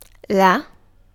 Ääntäminen
Vaihtoehtoiset kirjoitusmuodot (rikkinäinen englanti) they (rikkinäinen englanti) dey (vanhahtava) thair (rikkinäinen englanti) thur Synonyymit thither Ääntäminen UK : IPA : [ðɛː] US : IPA : [ðɛɹ] UK : IPA : /ðɛə(ɹ)/ IPA : /ðɛː(ɹ)/ US : IPA : /ðɛɚ/ Tuntematon aksentti: IPA : /ðɛə/